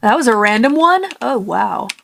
Worms speechbanks
Stupid.wav